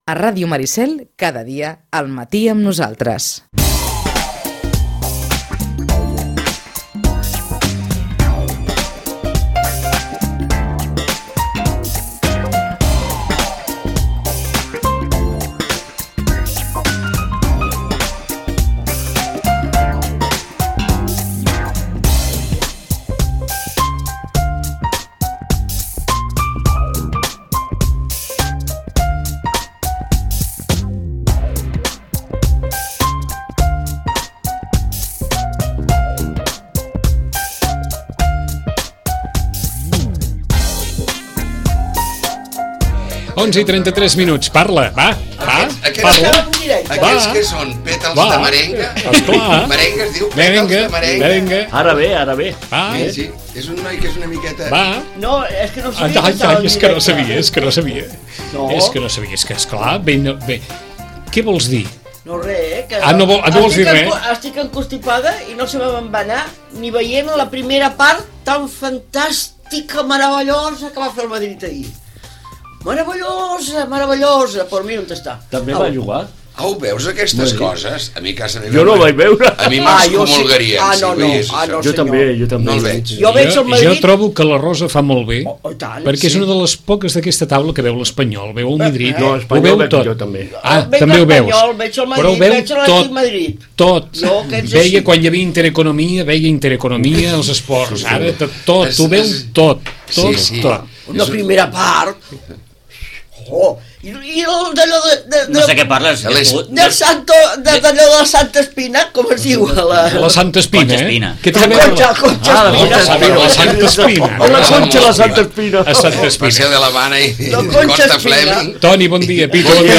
La tertúlia esportiva dels divendres